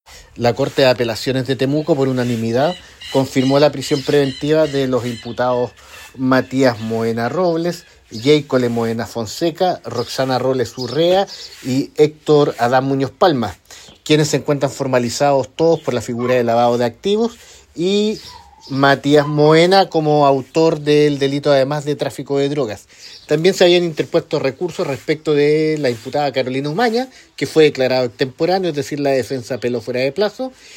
La información la confirmó a Radio Bío Bío el fiscal de Lautaro, Miguel Ángel Velásquez, que dirige esta investigación.